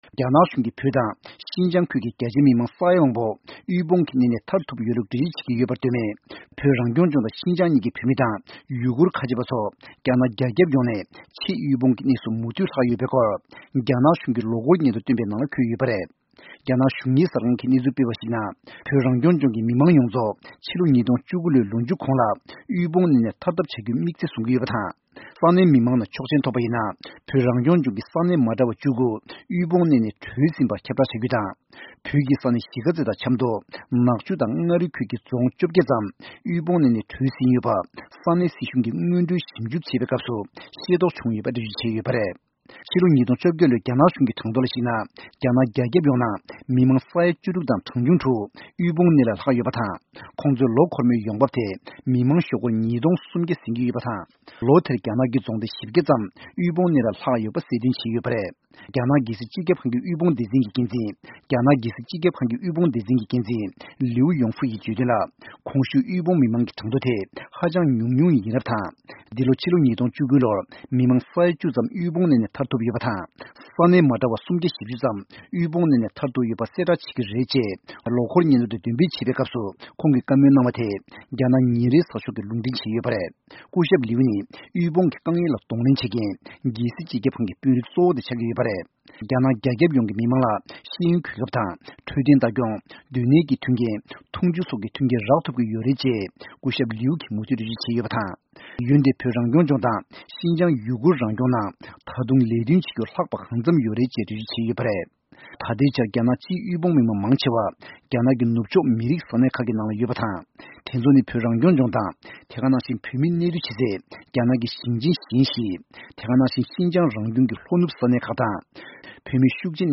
གནས་ཚུལ་སྙན་སྒྲོན་ཞུ་ཡི་རེད།།